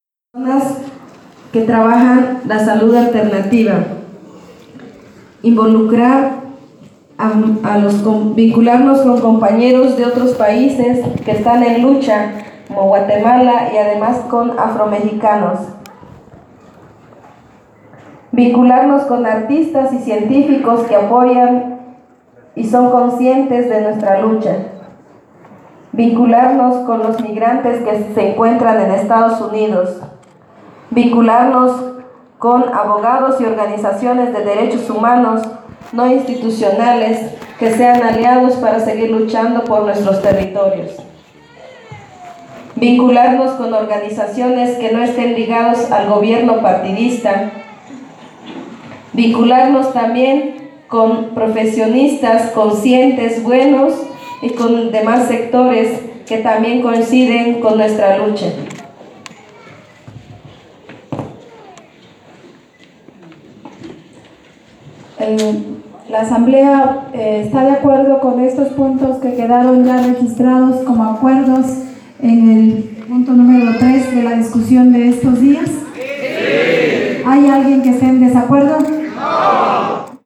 Aquí les compartimos los audios de la sesión pública de la Asamblea Constitutiva del Consejo Indígena de Gobierno para México, realizada el 28 de mayo de 2017 en el CIDECI-Unitierra en San Cristóbal de las Casas, Chiapas: